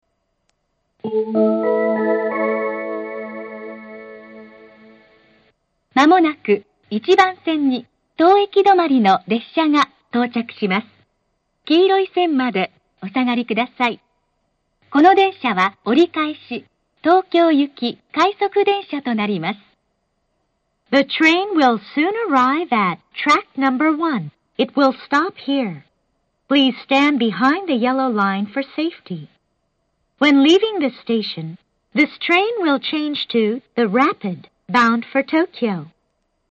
メロディーは成田空港２駅のオリジナルのものを使用しており、藤沢４番線の曲に似ています。
１番線接近放送 当駅折り返し快速東京行の放送です。
１番線発車メロディー 発車放送は成田エクスプレス２６号新宿行です。
narita-airport-1bannsenn-sekkinn.mp3